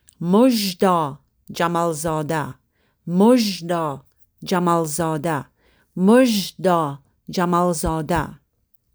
(Avec prononciation audio)